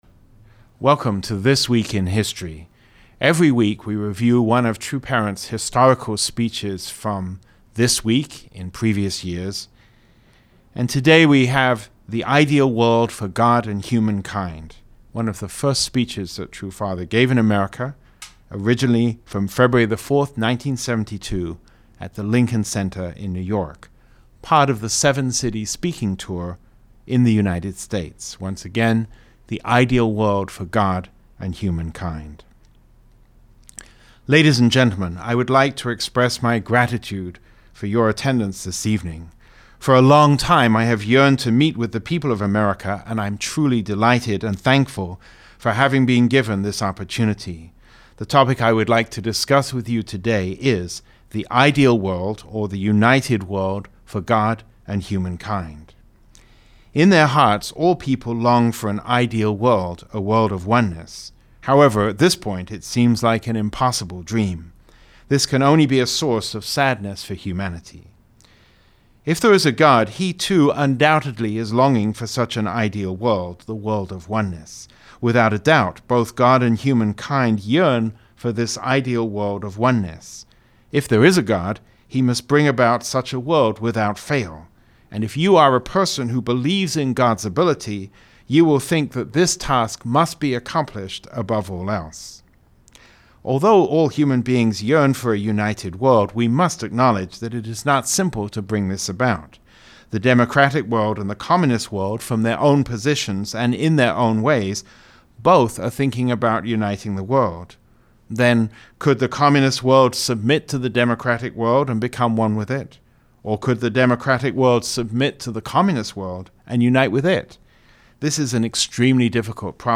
True Father spoke on February 4, 1972 at the Lincoln Center in New York City during the Seven-City Speaking tour in the United States.